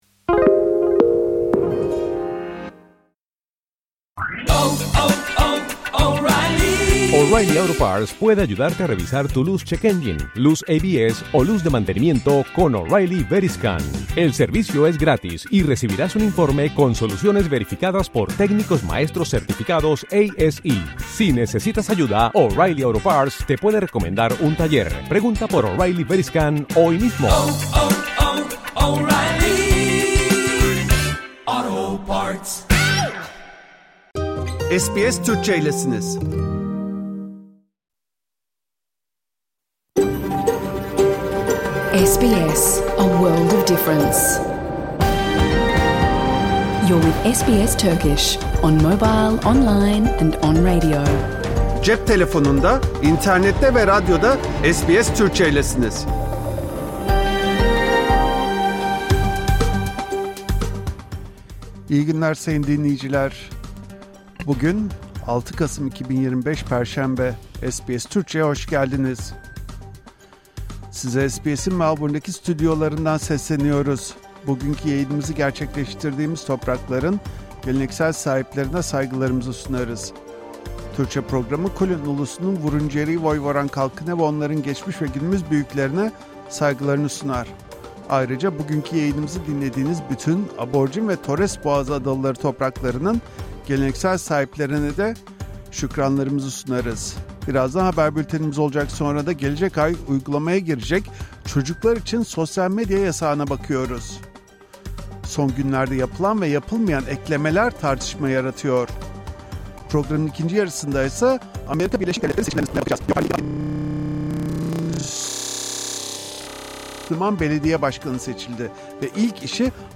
Hafta içi Salı hariç her gün Avustralya doğu kıyıları saati ile 14:00 ile 15:00 arasında yayınlanan SBS Türkçe radyo programını artık reklamsız, müziksiz ve kesintisiz bir şekilde dinleyebilirsiniz. 🎧
🎧 PROGRAM İÇERİĞİ SBS Türkçe haber bülteni. Gelecek ay Avustralya’da çocukları korumayı amaçlayan yeni sosyal medya kuralları devreye giriyor. 10 Aralık’tan sonra 16 yaşından küçük çocuklara dokuz sosyal medya platformu yasak olacak.